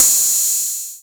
Index of /90_sSampleCDs/AKAI S6000 CD-ROM - Volume 3/Crash_Cymbal1/FX_CYMBAL